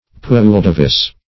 Pouldavis \Poul"da`vis\, n.